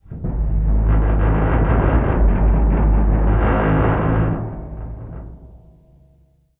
metal_low_creaking_ship_structure_07.wav